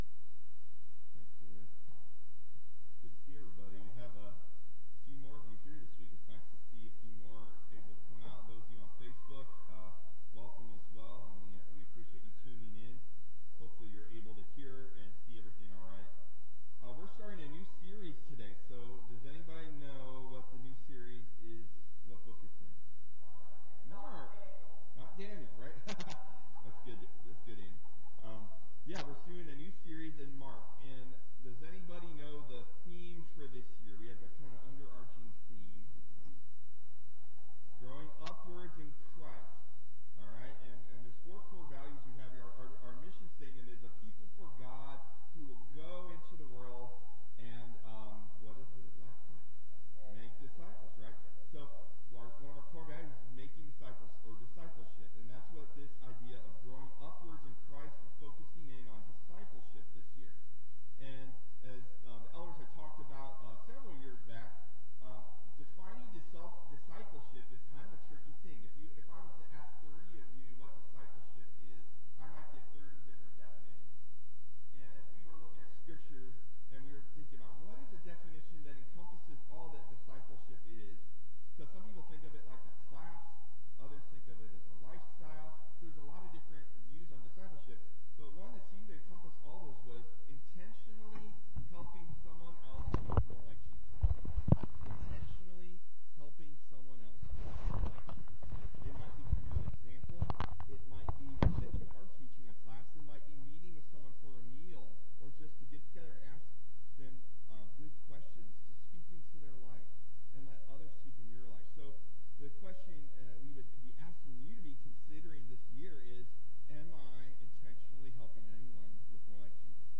Sermon: Respond to and proclaim the gospel of Jesus Christ the Son of God!